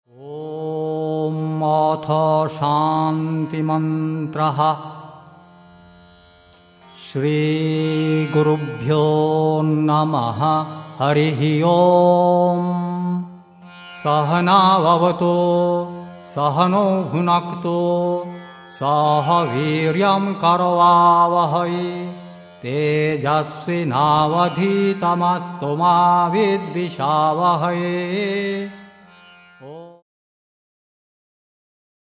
(Vedic mantras in chant and song)